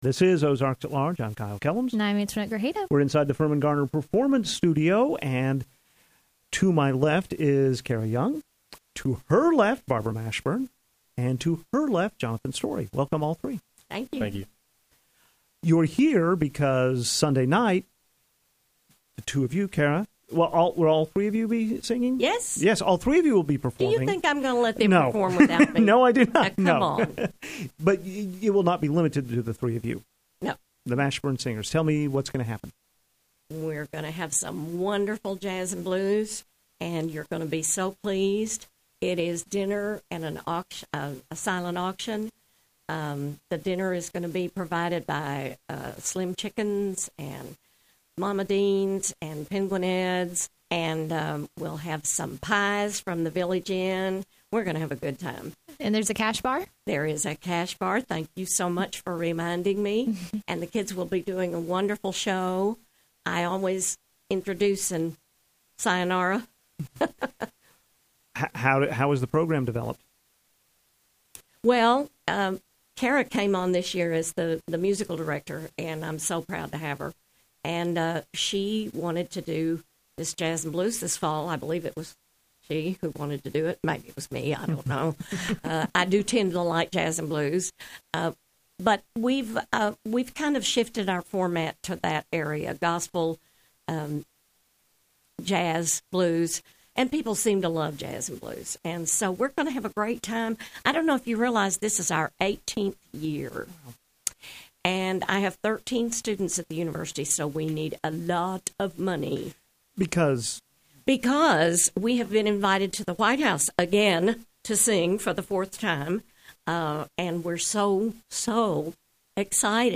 The Mashburn Singers visited KUAF’s Firmin-Garner Performance Studio to give us a preview of their Sunday night performance at the UARK Bowl.